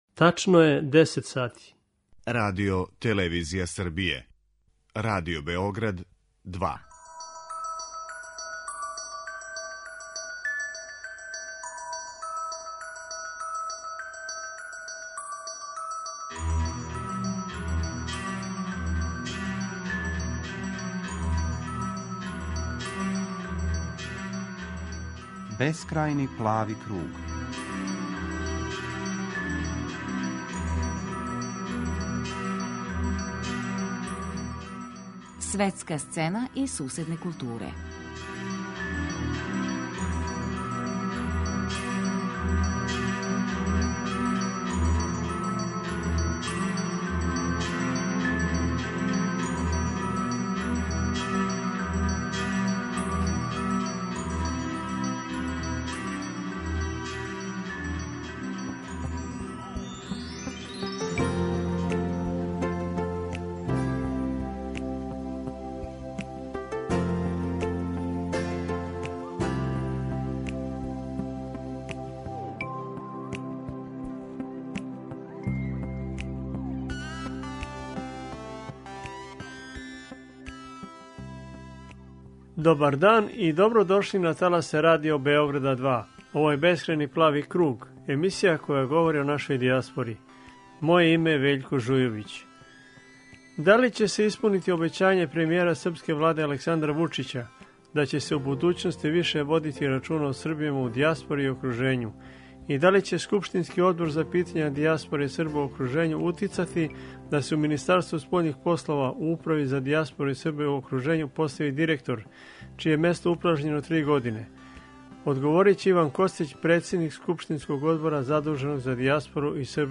На ова питања одговара Иван Костић, председник Скупштинског одбора задуженог за дијаспору и Србе у окружењу.
Чућемо разговор са Александром Чотрићем, а Савез Срба у Румунији традиционално организује књижевно духовну манифестацију "Дани Преображења", на којој се у неколико дана представљају књижевници, ликовни и музички уметници.